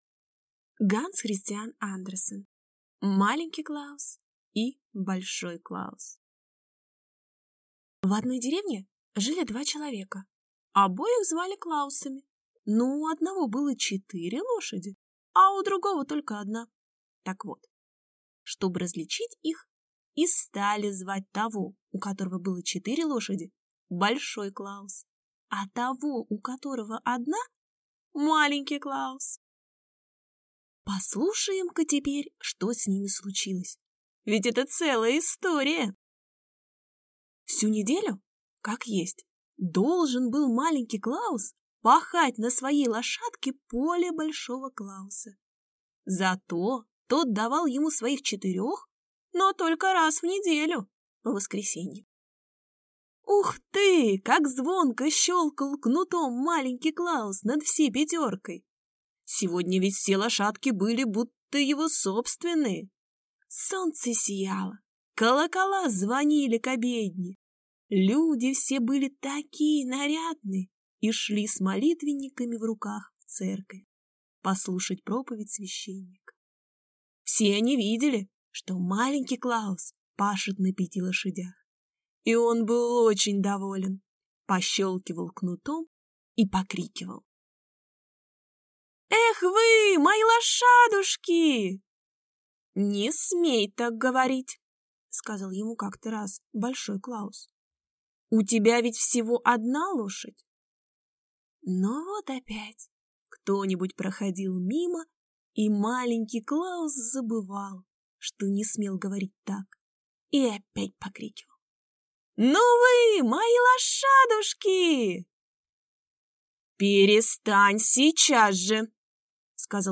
Аудиокнига Маленький Клаус и большой Клаус | Библиотека аудиокниг